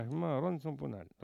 Maraîchin
locutions vernaculaires
Catégorie Locution